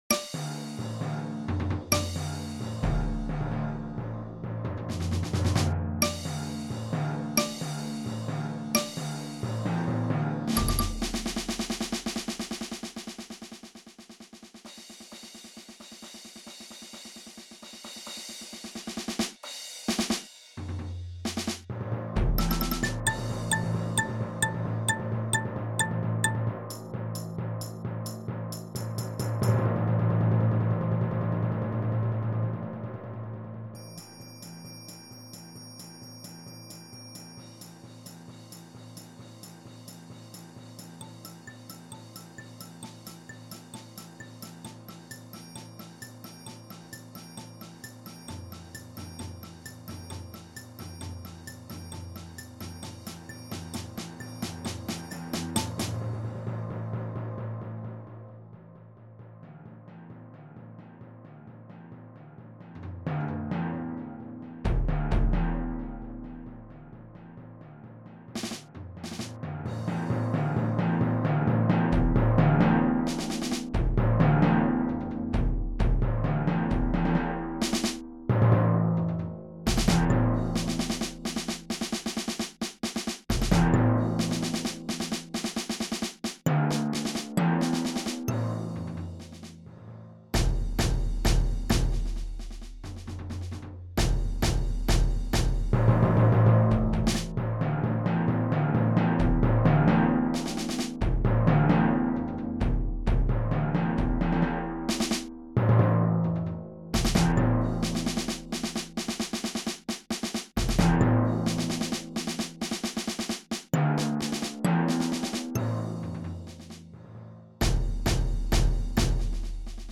MIDI audio